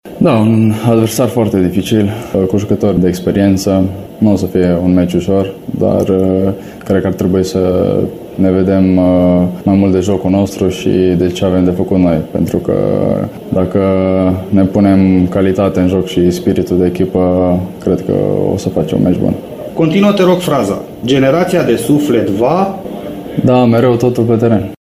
Tricolorii întâlnesc Bosnia și Herțegovina, în jocul programat mâine, de la ora 21.45, pe Arena Națională din București, în prima etapă din grupa H. Despre adversarul de mâine și despre echipa României a vorbit timișoreanul Marius Marin, jucătorul echipei italiene Pisa.